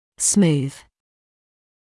[smuːð][смуːс]гладкий, ровный (о поверхности); плавный, ровный (о движении)